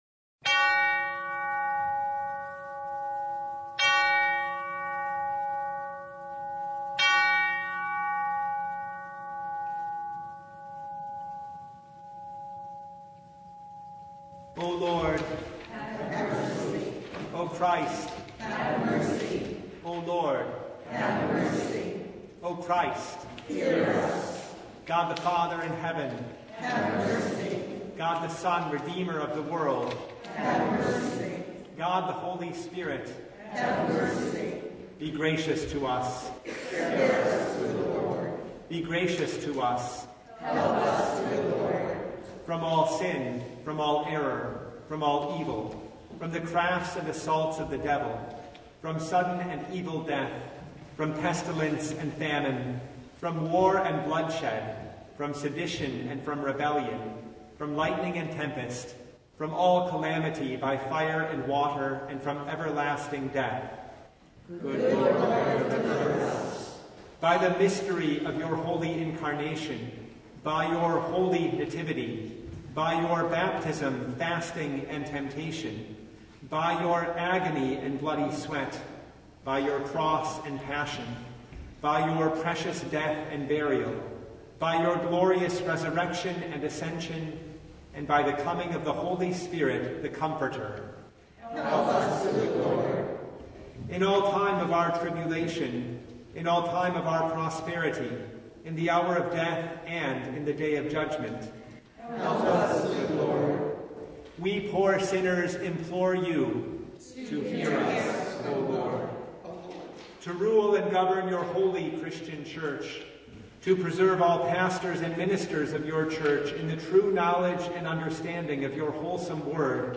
Passage: John 3:1-13 Service Type: Lent Midweek Noon Download Files Bulletin Topics: Full Service